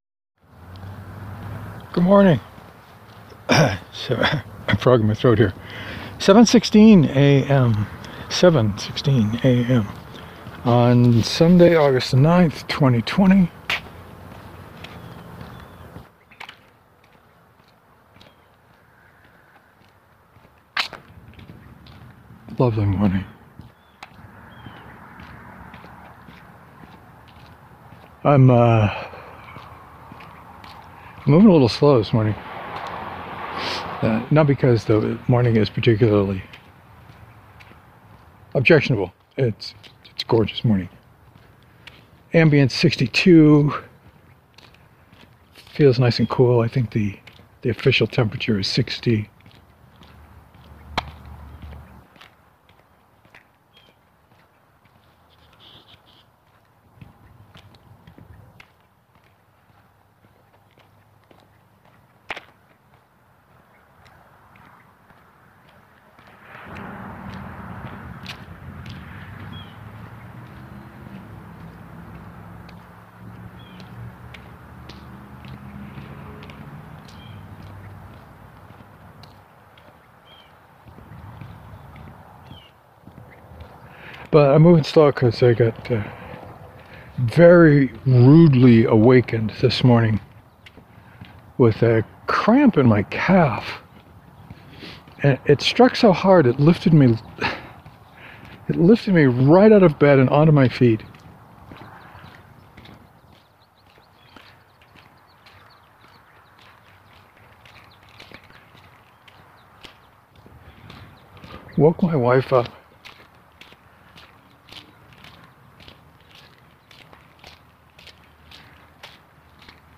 I spent the walk talking about the next book, Fallout 4, and the what I need to do this week to get ready for NovelRama next weekend.